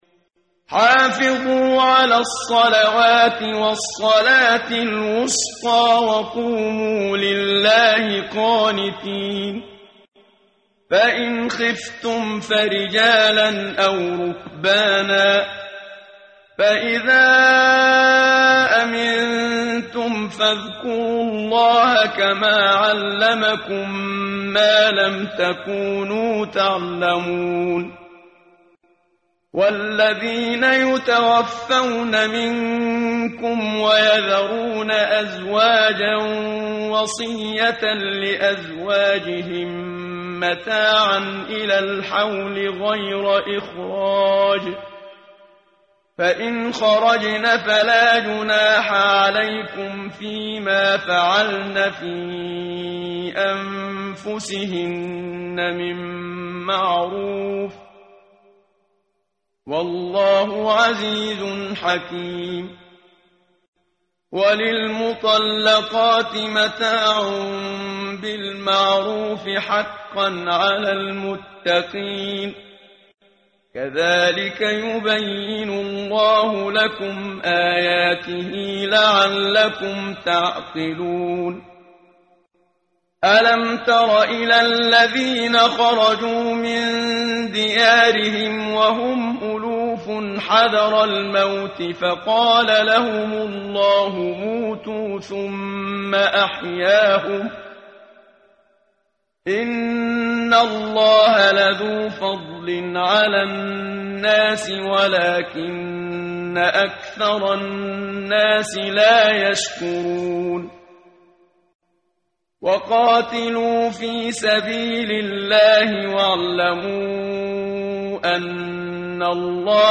ترتیل
ترتیل صفحه 39 سوره مبارکه بقره (جزء دوم) از سری مجموعه صفحه ای از نور با صدای استاد محمد صدیق منشاوی